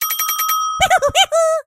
P先生只会像一只企鹅发出无意义的叫声，但是可以从中听出情绪。
Media:mrp_minip_spawn_03.ogg Mr. P laughs
P先生的笑声